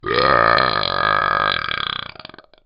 Talking Ben Burp 3 Sound Effect Free Download
Talking Ben Burp 3